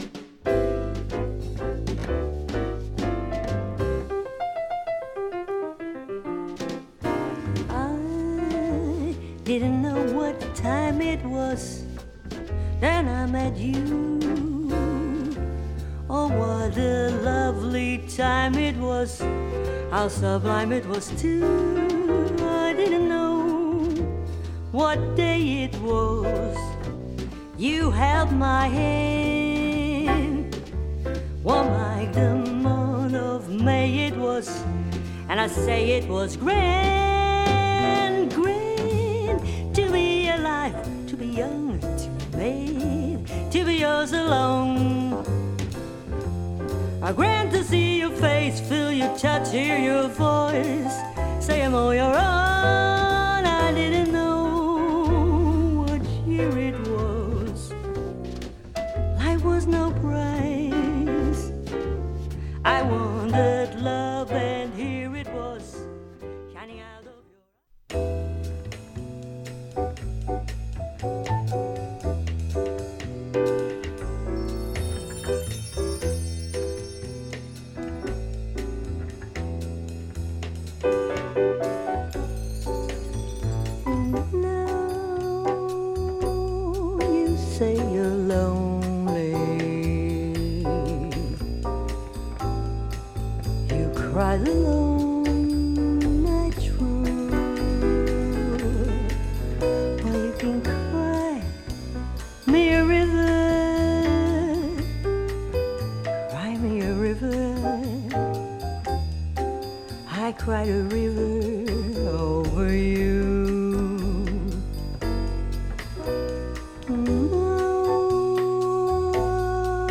端正ジャズボサ演奏が素晴らしい
リラクシン・ジャズの極みと云えそうな